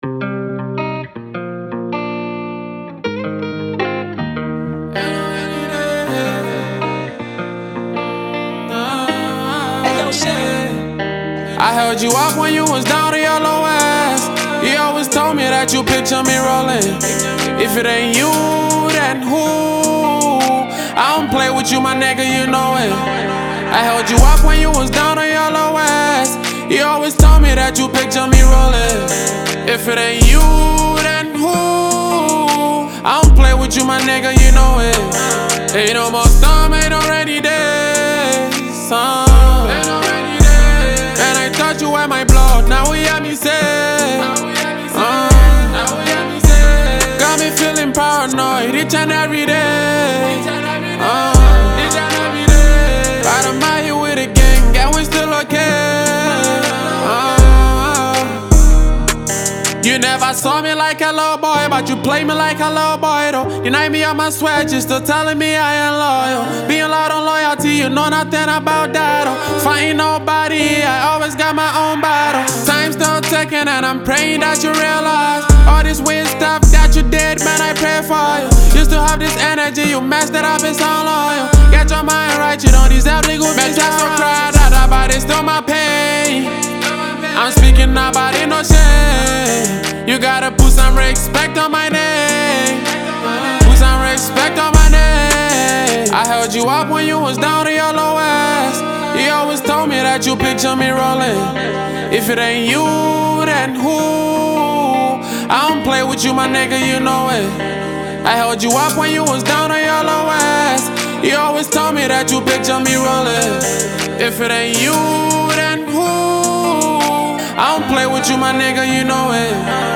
a talented Ghanaian rapper
studio tune